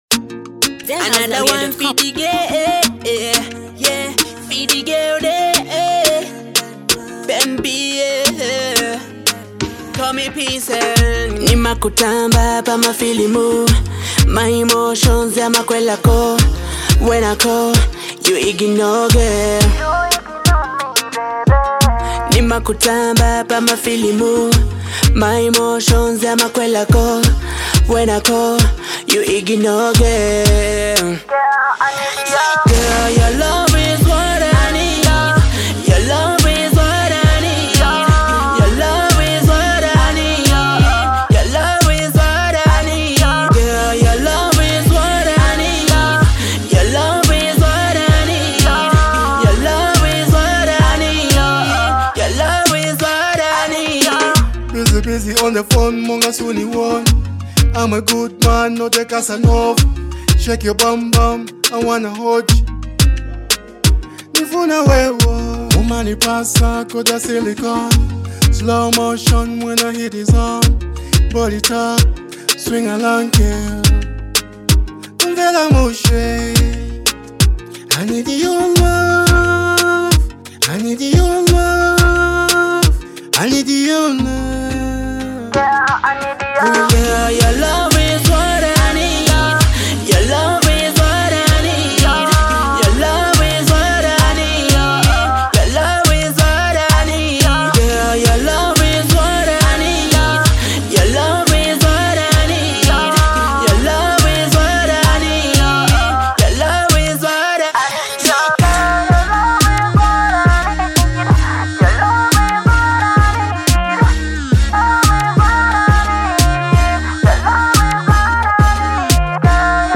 a heartfelt track that dives deep into love